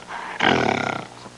Gorilla (short) Sound Effect
gorilla-short.mp3